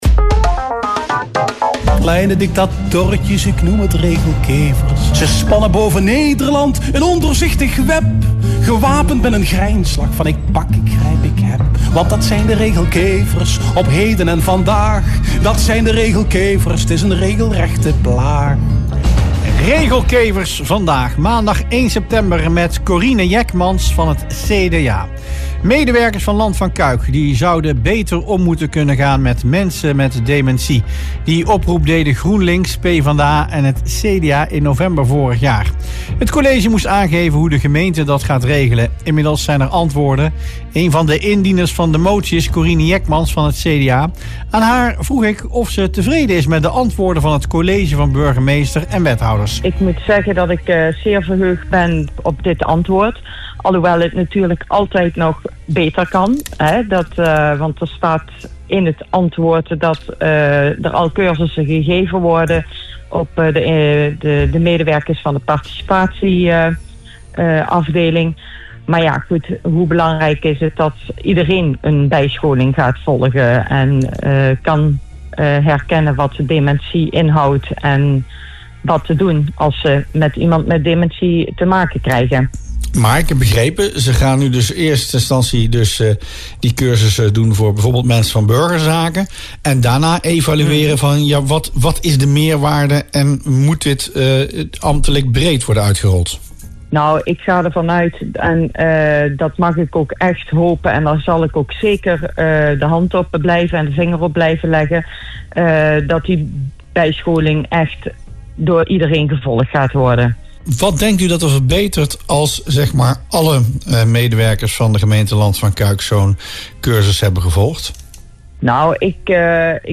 CDA-raadslid Corine Jeckmans zei in radioprogramma Rustplaats Lokkant tevereden te zijn, al wil ze wel een nog wat bredere aanpak.